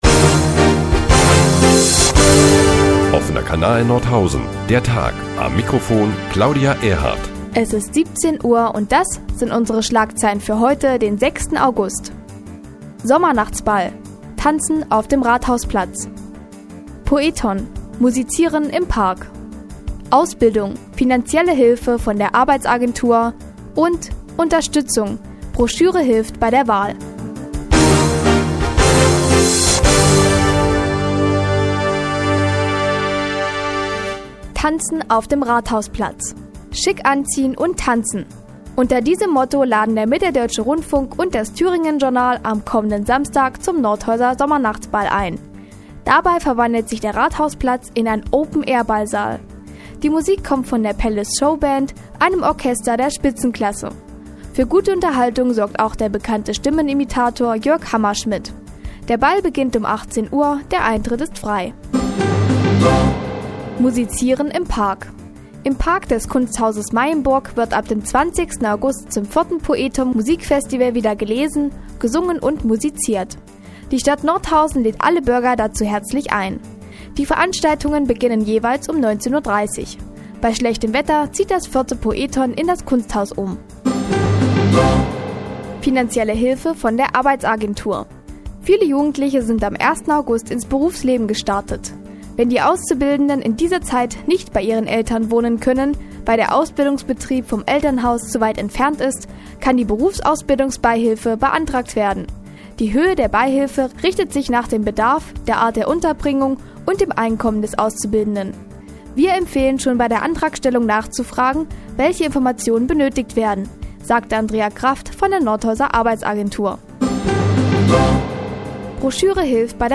Die tägliche Nachrichtensendung des OKN ist nun auch in der nnz zu hören. Heute unter anderem mit einem Sommernachtsball, Ausbildungsunterstützung und Wahlhilfe.